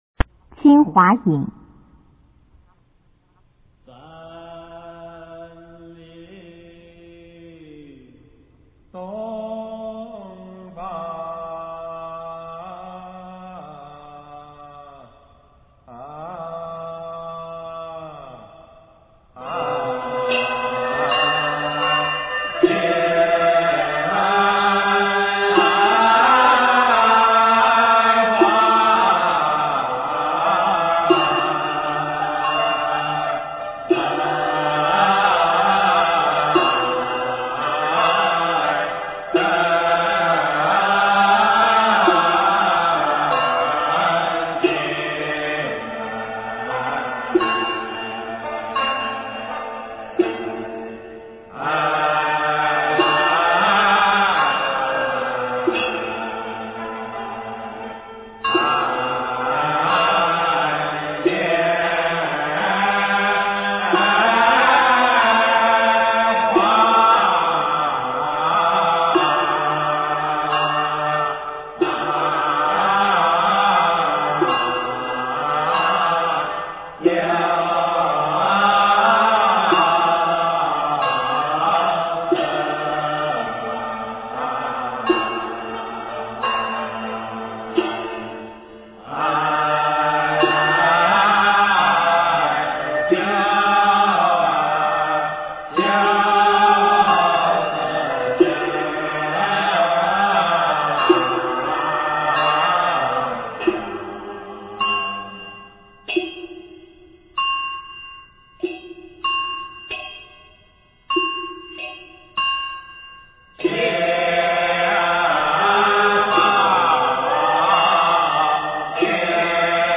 用途：青华引为阴事所用的阴韵，赞颂救苦天尊，表现出救苦天尊的慈悲，护佑群下，使之获得原赦的欢快之情。